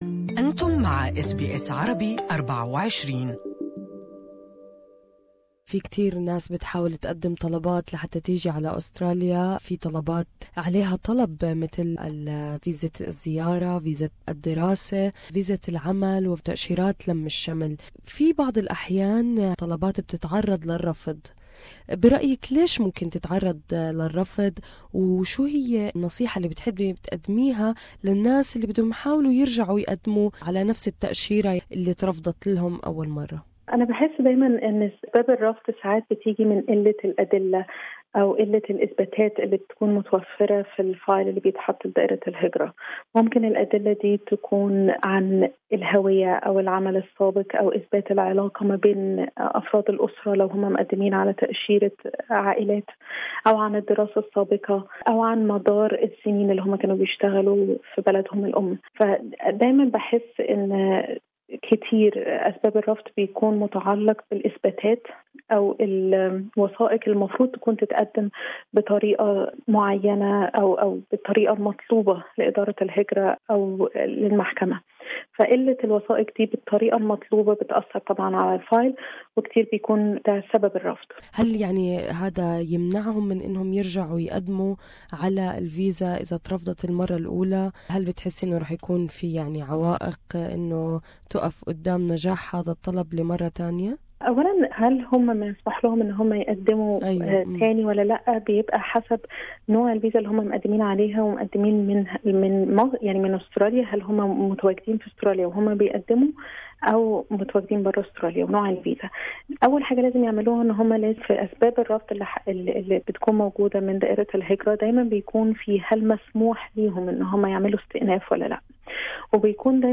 لا يعد رفض طلب التأشيرة الأسترالية للمرة الأولى أمرا حاسما، اذ أن هناك سبل متوفرة للتقديم مجددا تعرفوا عليها في المقابلة التالية.